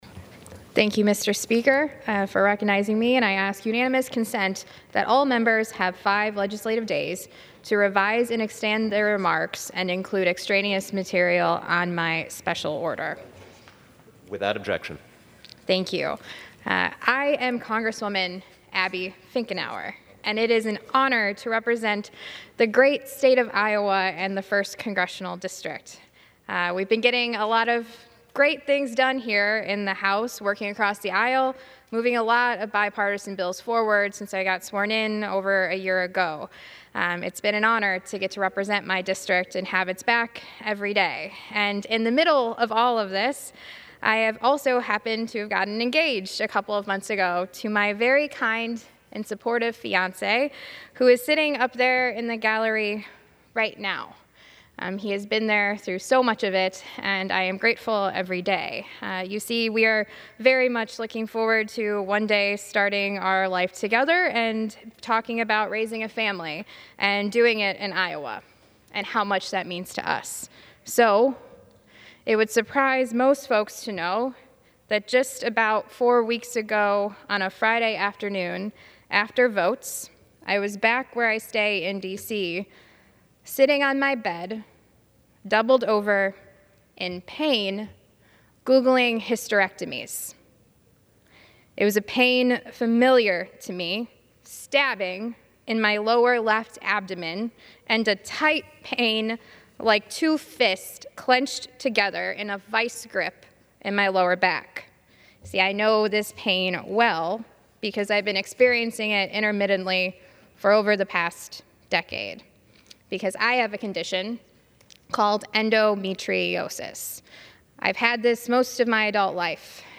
Finkenauer gave a speech on the floor of the U.S. House today, describing the painful condition.
AUDIO of Finkenauer’s remarks